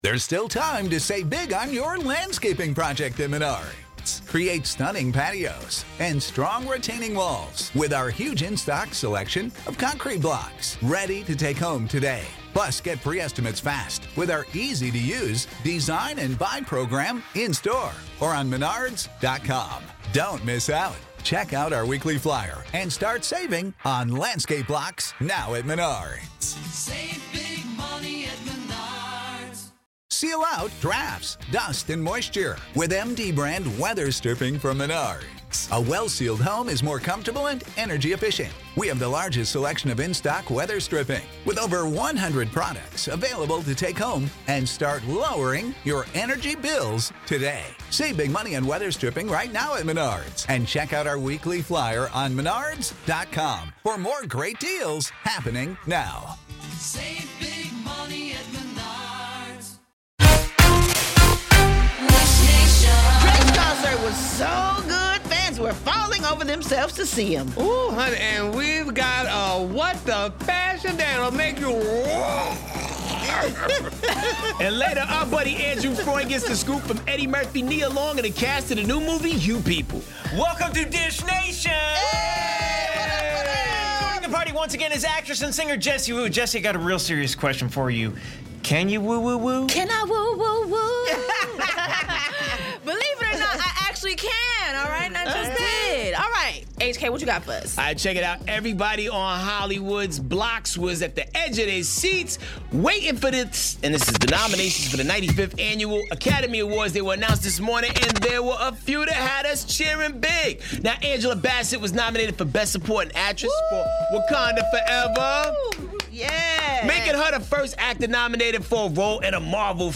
Viola Davis gets snubbed by The Oscars while Angela Bassett makes Marvel history! Plus, Kylie Jenner gives King of Zamunda a run for his money and we dish with 'You People' stars Eddie Murphy, Nia Long, and more! Jessie Woo co-hosts, so tune in to today's Dish Nation!